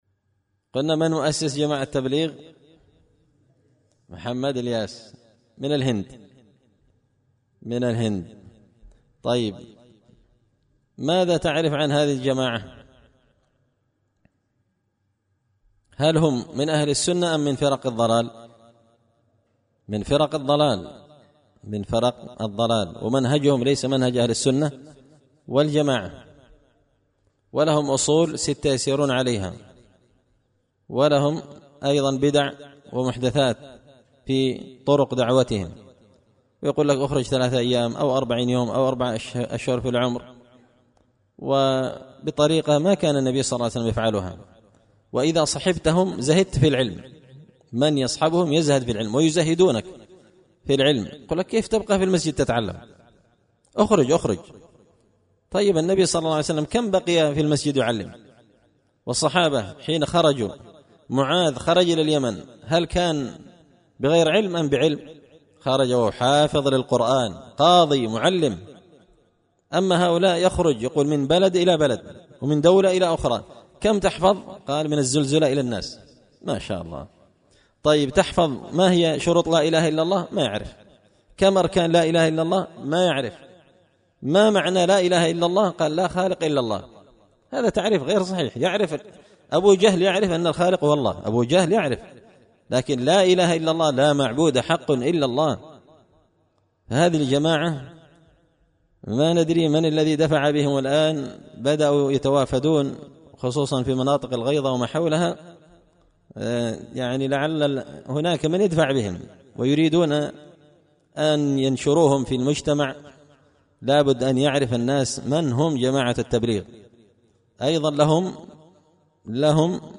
خطبة جمعة بعنوان – تحذير من جماعة التبليغ
دار الحديث بمسجد الفرقان ـ قشن ـ المهرة ـ اليمن